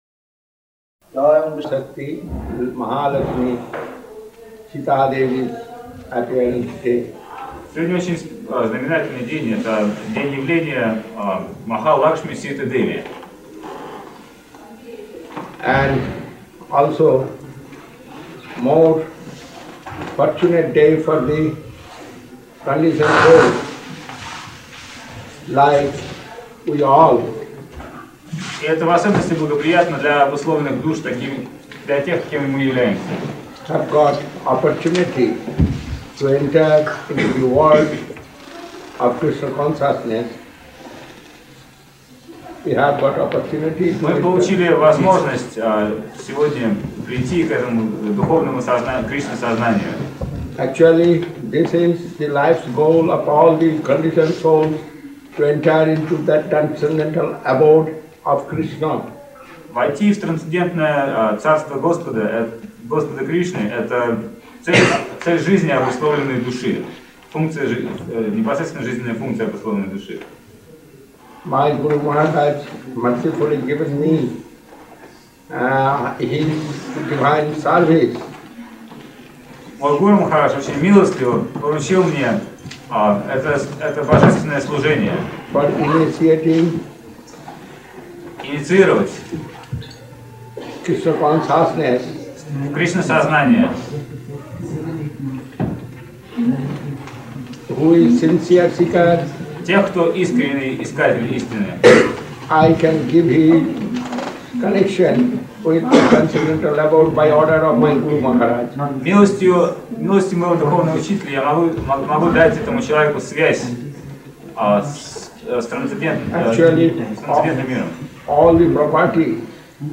Четыре регулирующих духовную жизнь принципа. Сознание Кришны избавляет от иллюзии материального мира. Бхаджан "Бхаджаху ре мана".
Место: Культурный центр «Шри Чайтанья Сарасвати» Москва